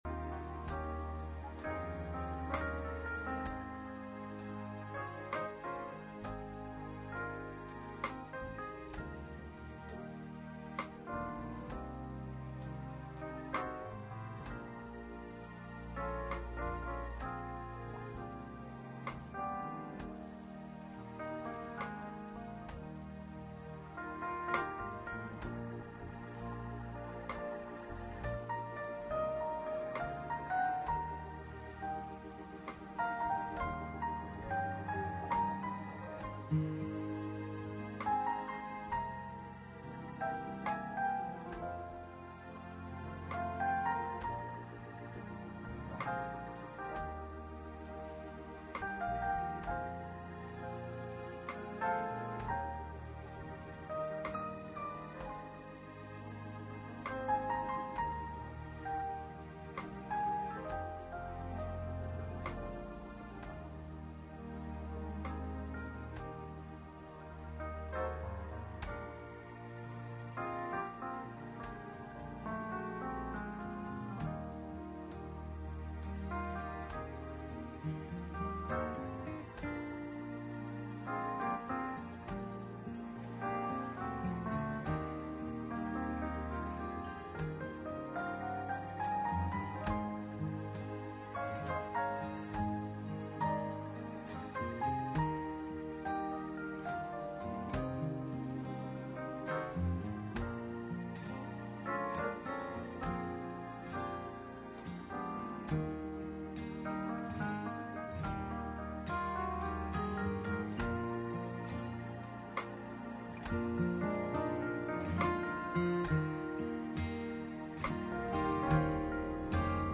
For this or any other sermon on DVD, please contact the library using the contact form on the website.